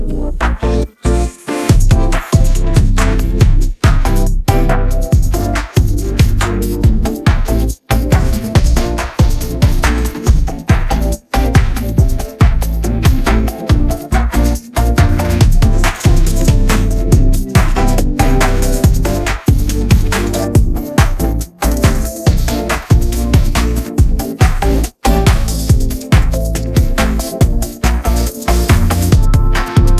audio-to-audio k-pop music-generation